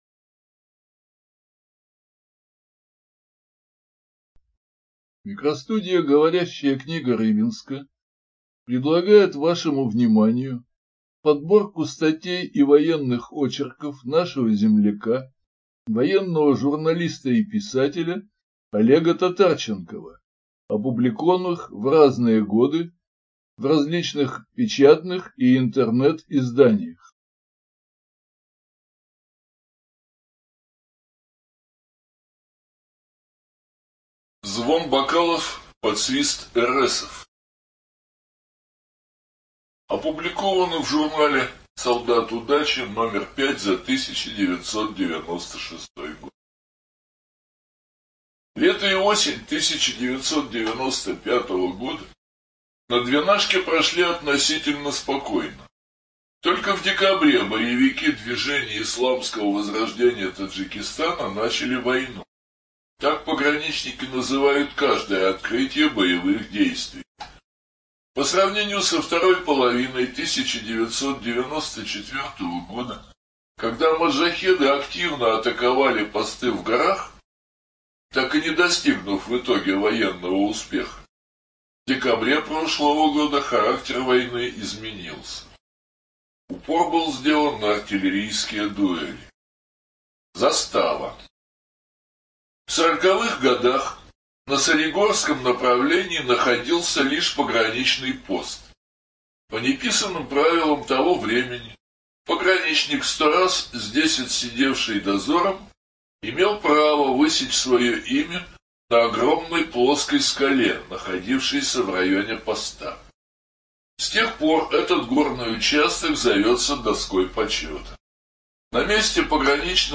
Студия звукозаписиГоворящая книга Рыбинска